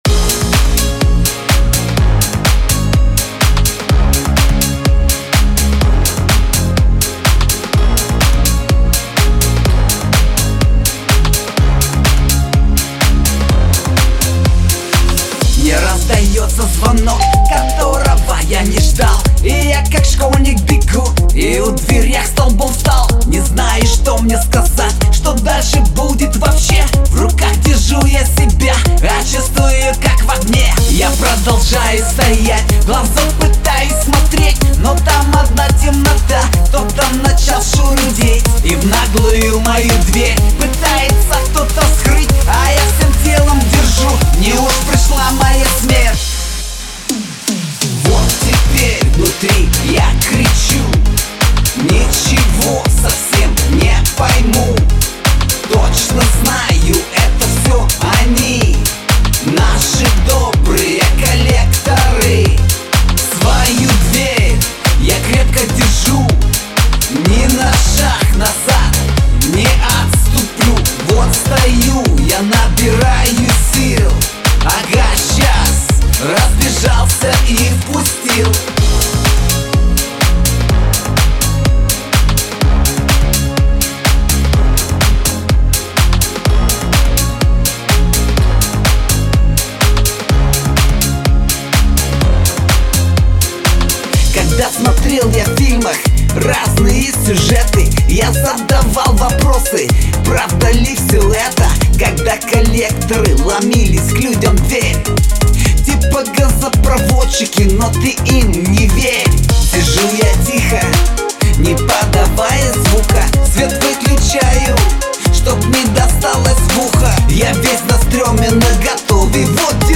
• Жанр: Хип-хоп
• Жанр: Рэп